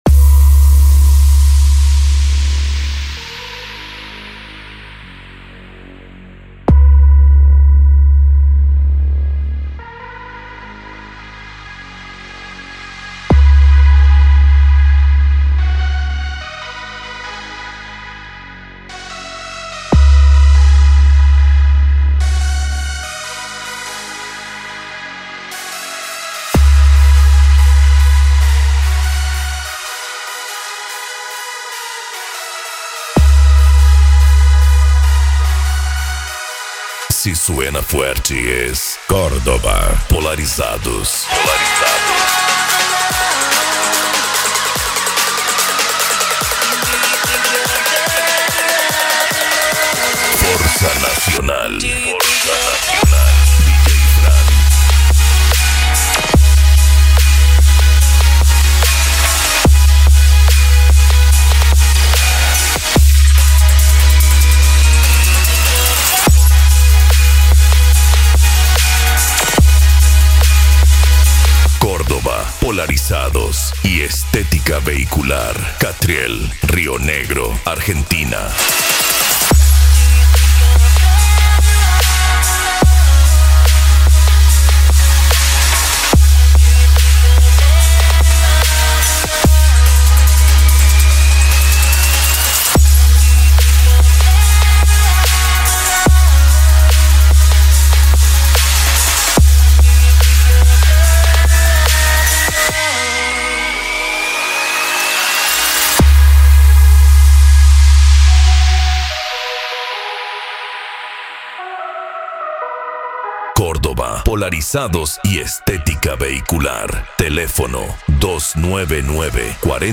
Bass
Electro House
Eletronica
Musica Electronica